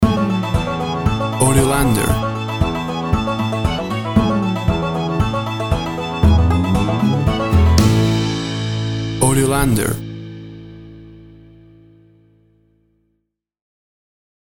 Classic country music sound.
Tempo (BPM) 115